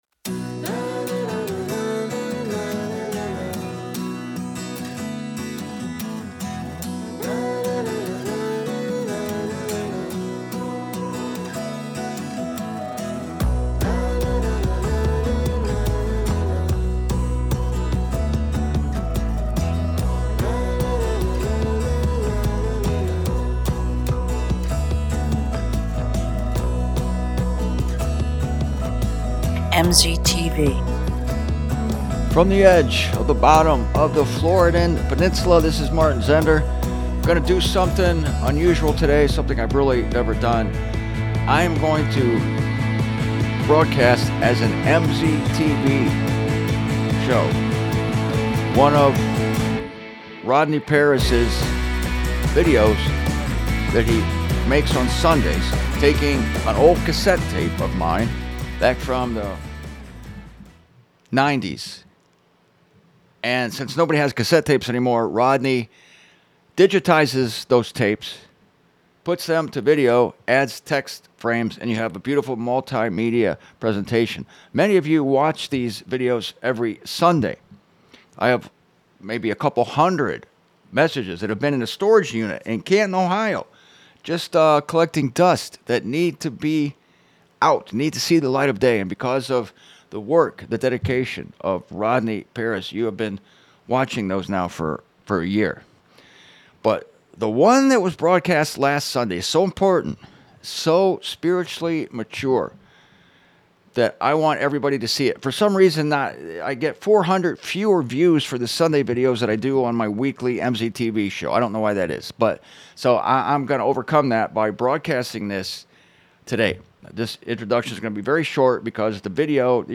I recorded this in 1996 in Kitchener, Ontario, Canada.